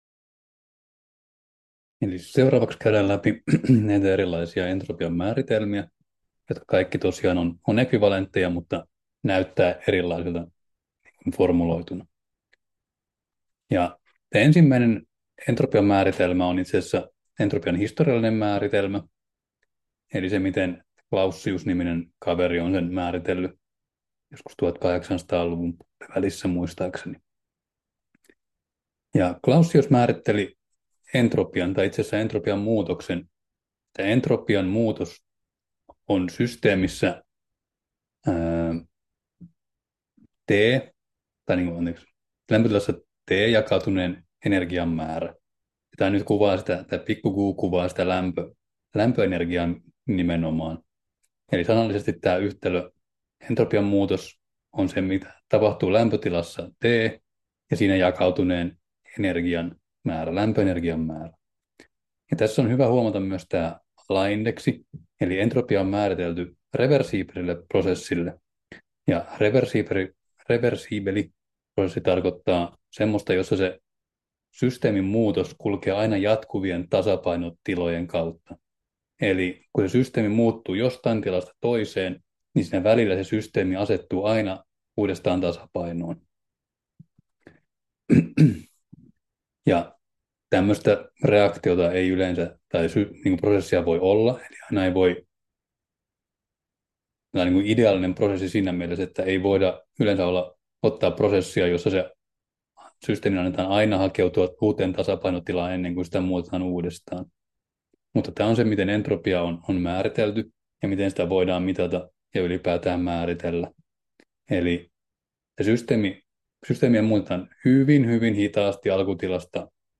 Luento 5: Entropia 3 — Moniviestin